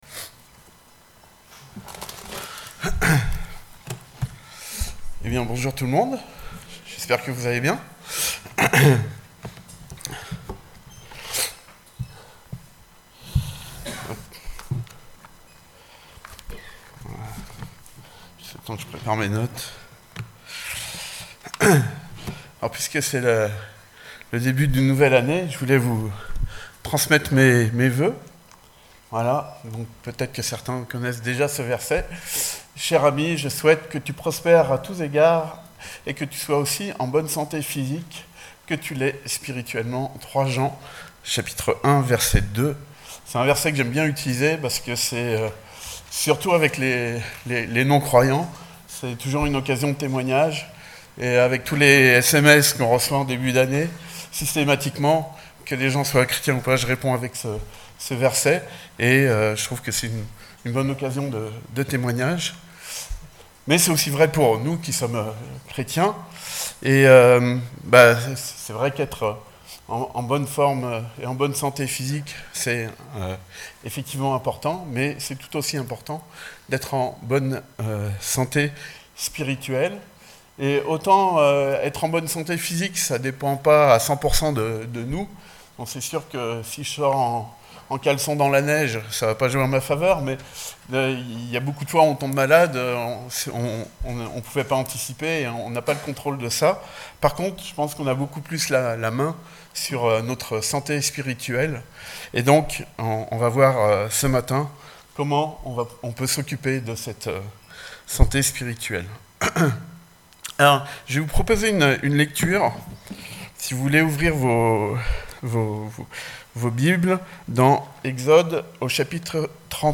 Culte Dimanche